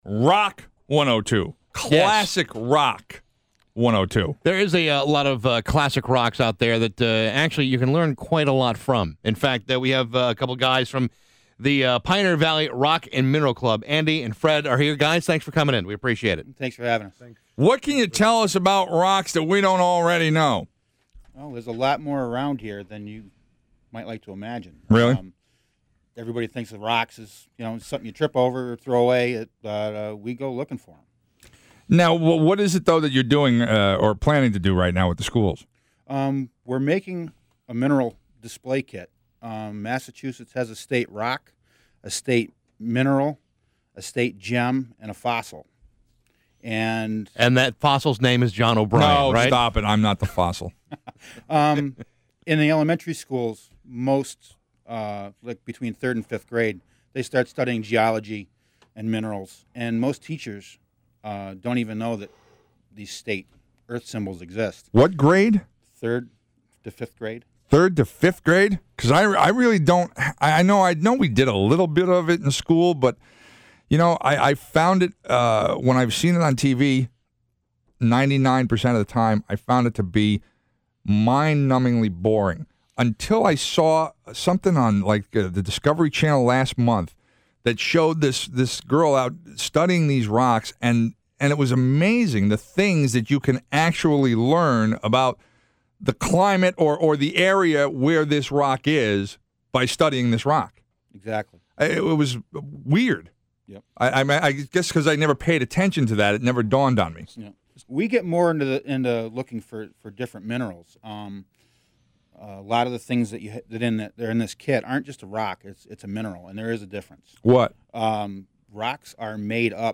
WAQY; Rock 102 radio interview (Audio) (5:45 min.)